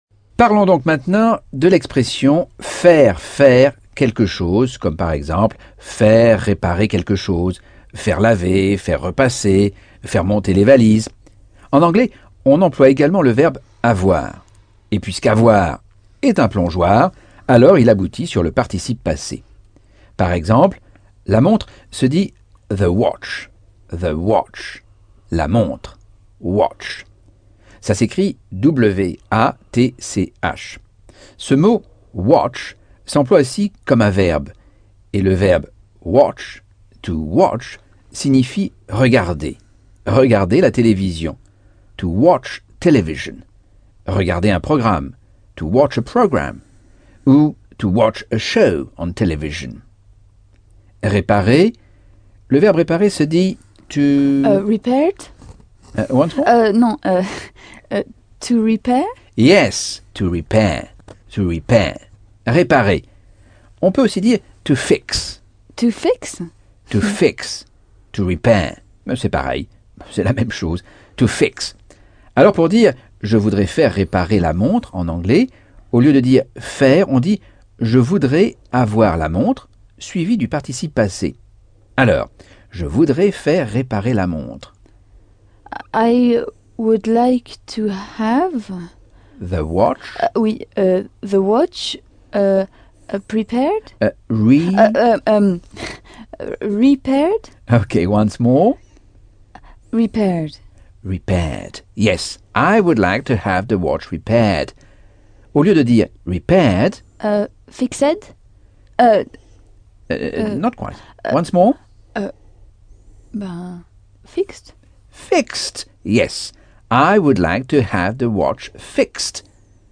Leçon 4 - Cours audio Anglais par Michel Thomas - Chapitre 10